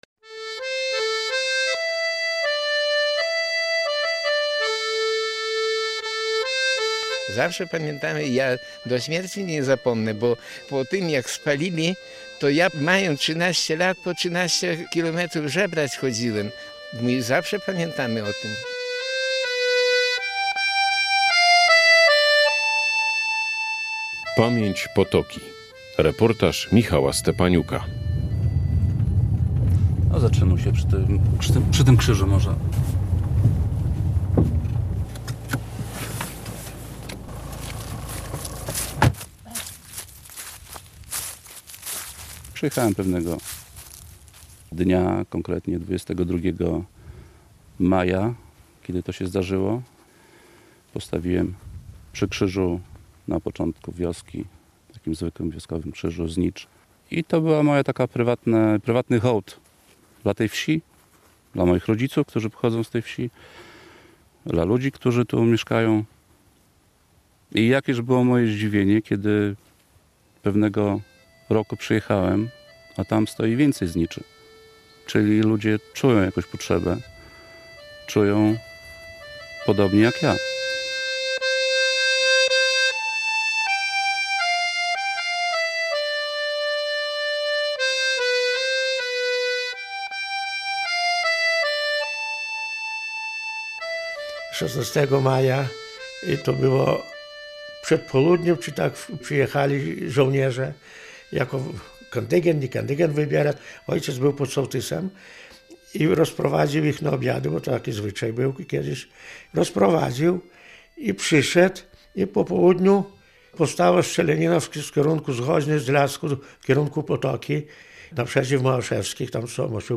Mieszkańcy Potoki wspominają wydarzenia z 1945 roku. Wieś została wówczas spalona przez oddział należący do V Wileńskiej Brygady AK.
Radio Białystok | Reportaż